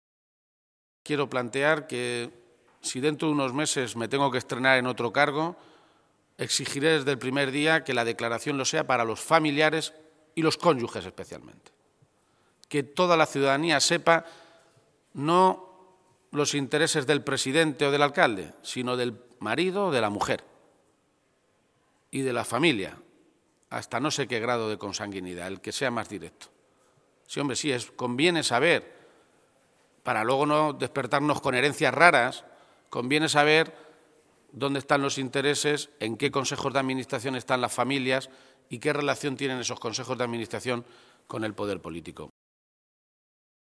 García-Page se pronunciaba de esta manera esta mañana, en Toledo, a preguntas de los medios de comunicación, durante la presentación de la web de Transparencia del Ayuntamiento de Toledo.
Cortes de audio de la rueda de prensa